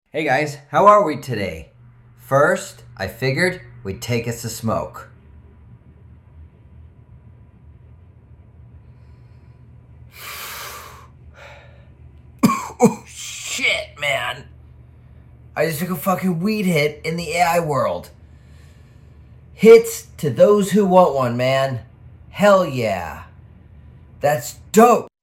This is text to speech only!! I just typed all this and said [inhale] [exhale] all that just assuming it wouldn't work.